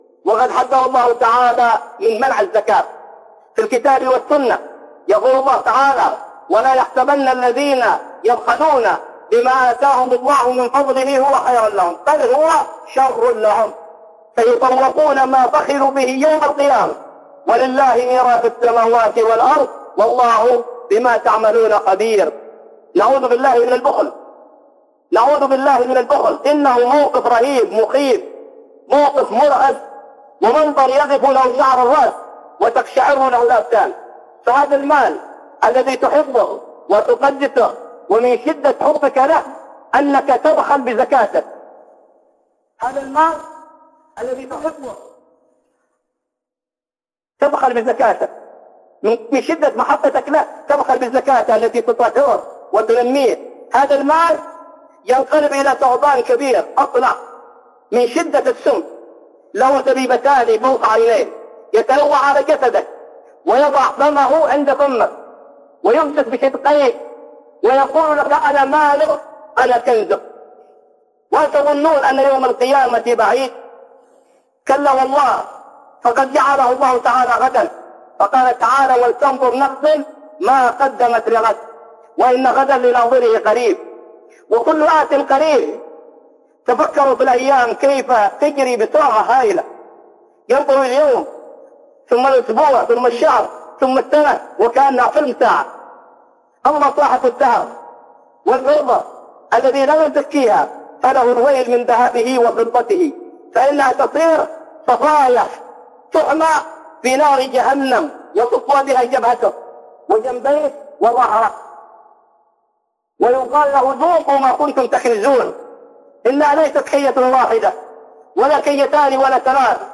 عقوبة مانع الزكاة في الآخرة - خطب